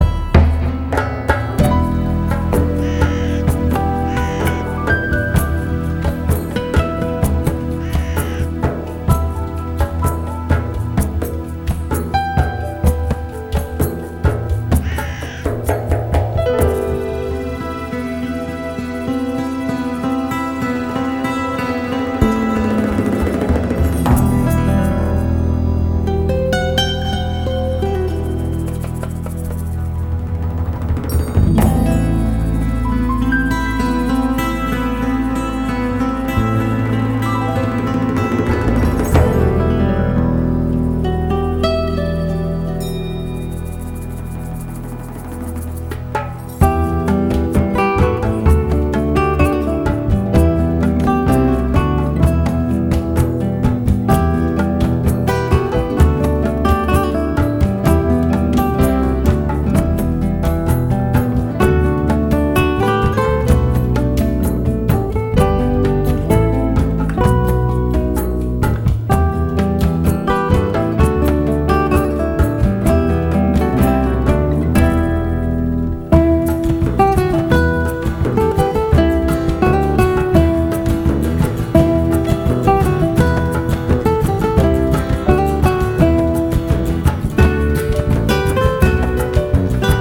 Percussion
Sopran-Saxophone
Guitar
Piano / Keyboards
Grand concertharp
Vocals